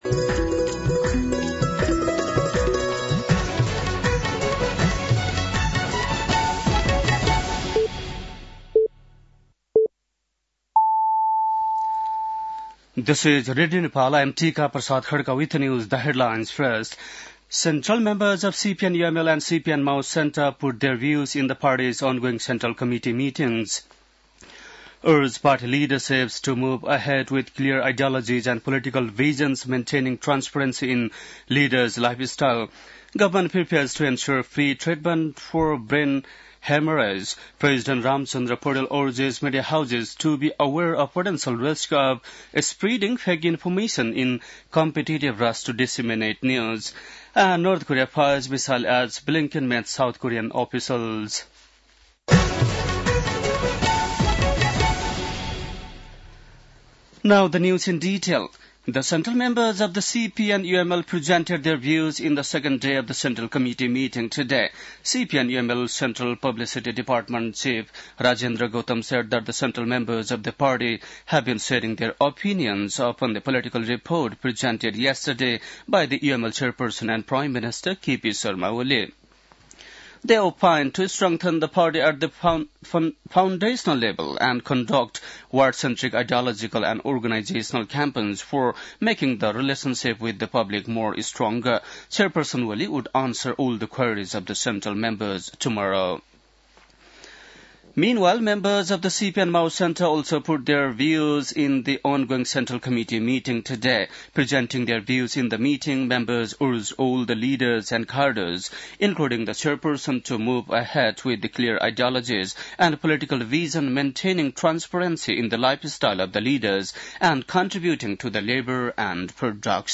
बेलुकी ८ बजेको अङ्ग्रेजी समाचार : २३ पुष , २०८१